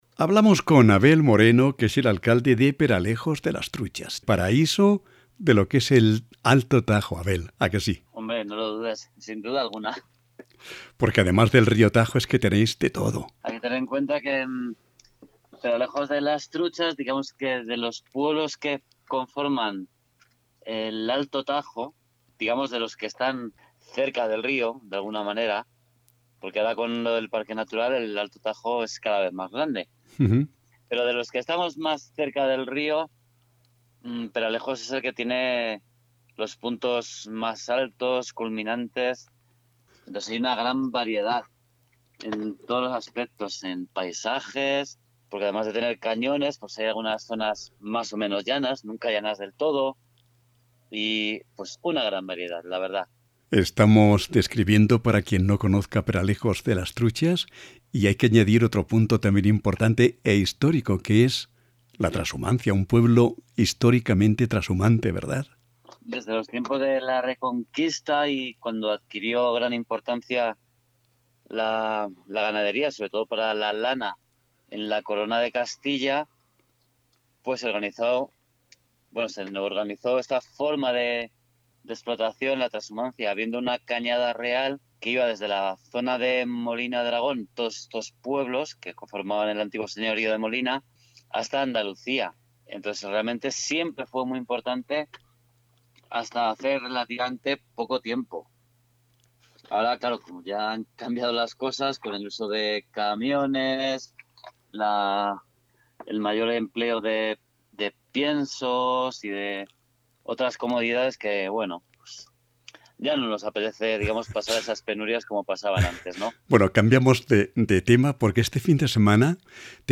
Pódcast (Audio). Entrevistamos a Abel Moreno, alcalde de Peralejos de las Truchas. La Virgen de Ribagorda y la fiesta ganchera de agosto